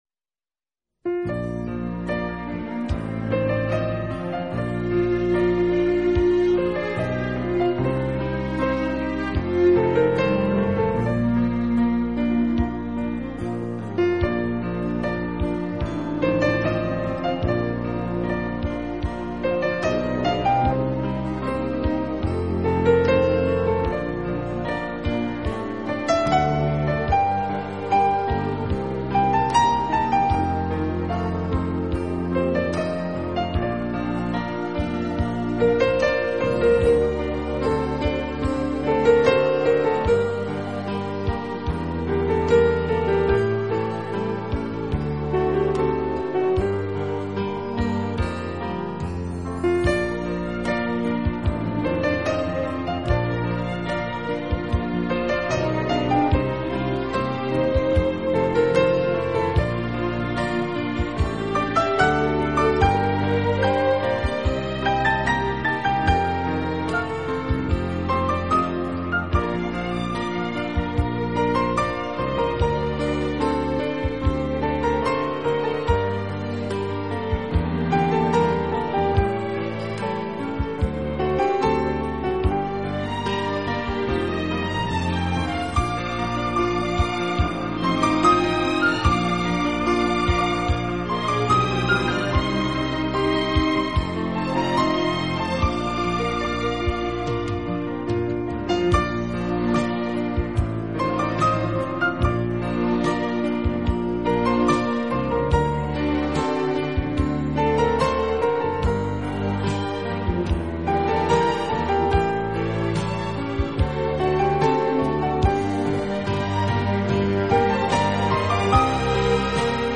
【浪漫钢琴】